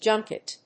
音節jun・ket 発音記号・読み方
/dʒˈʌŋkɪt(米国英語)/